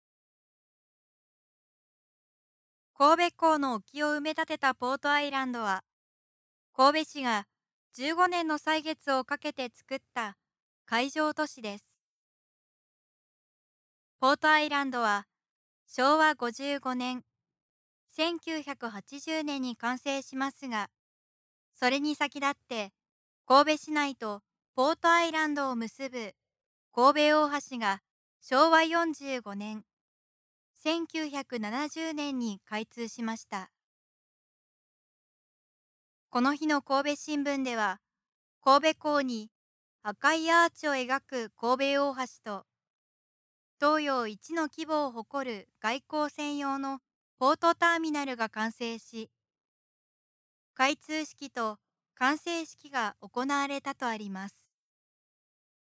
神戸市の歴史~年表~（音声ガイド）